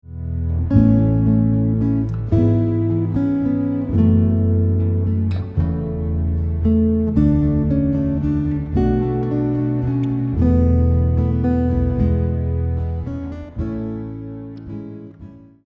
guitar arrangement preview